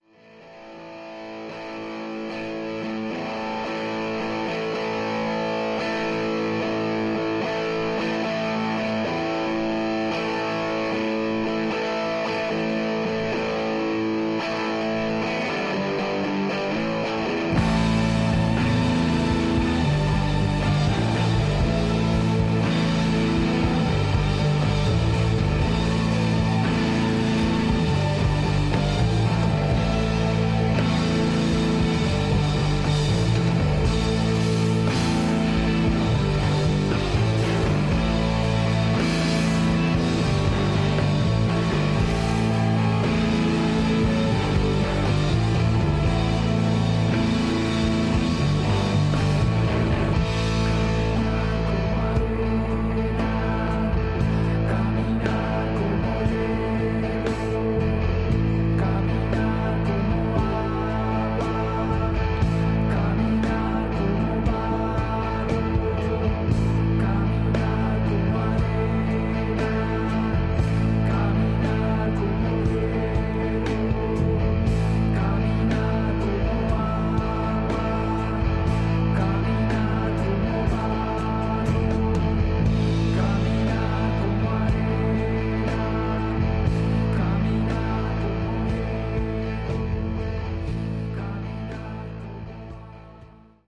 Tags: Rock , Psicodelico , Colombia , Bogotá